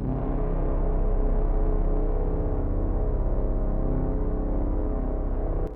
piano-sounds-dev
c2.wav